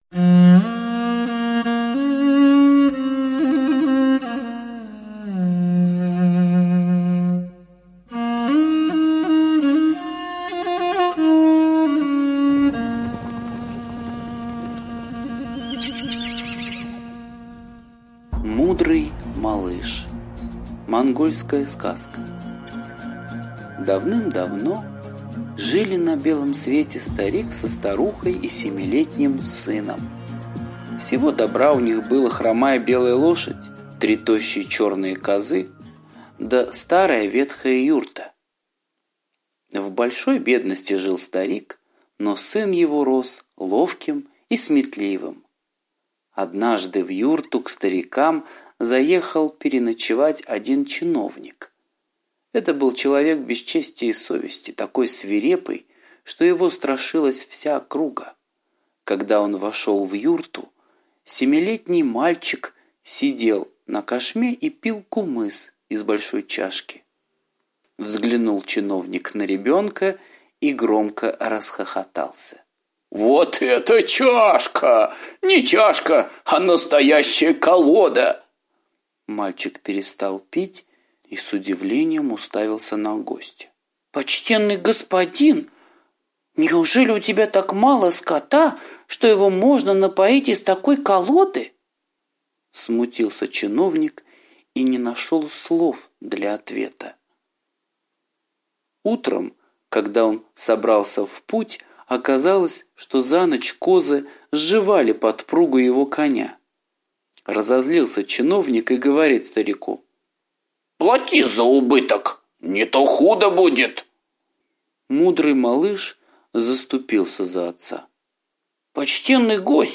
Мудрый малыш - восточная аудиосказка - слушать онлайн